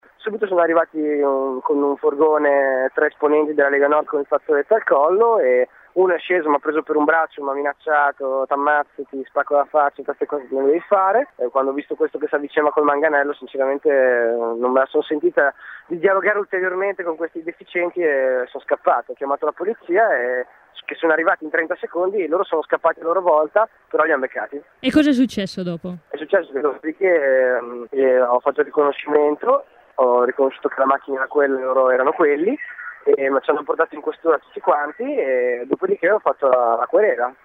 Ascolta l’intervista all’attivista aggredito